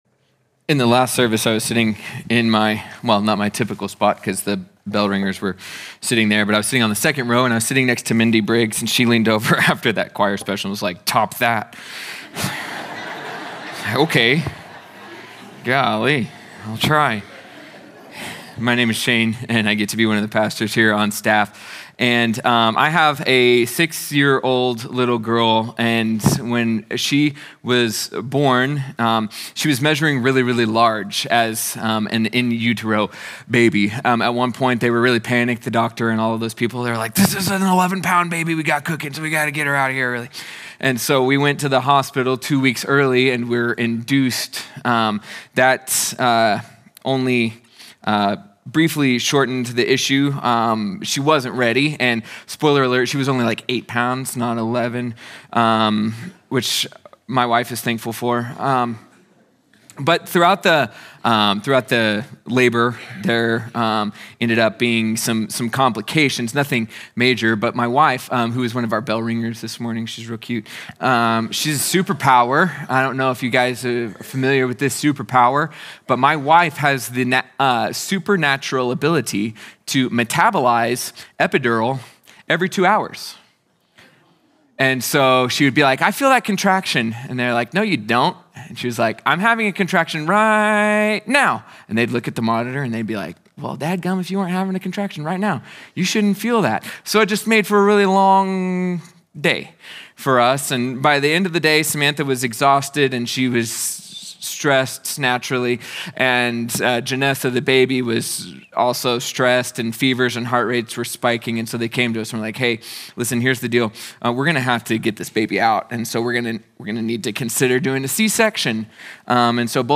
sermon audio 1221.mp3